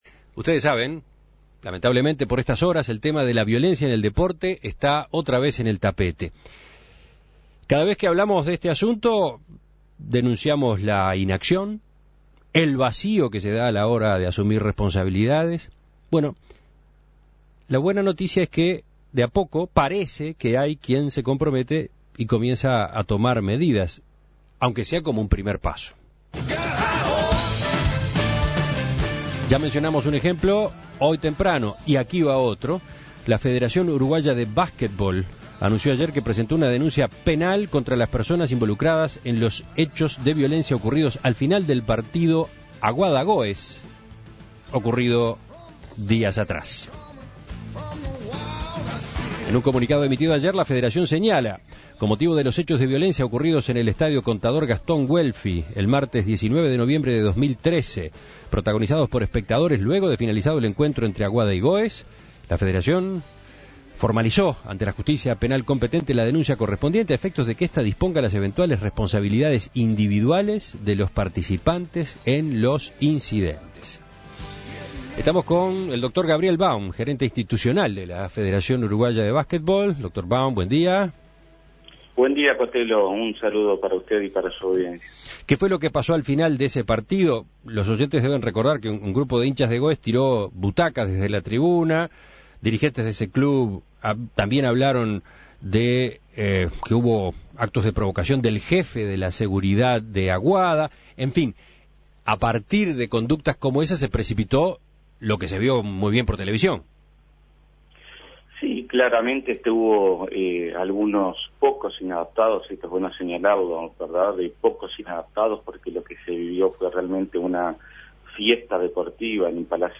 (emitido a las 9.24 Hs.)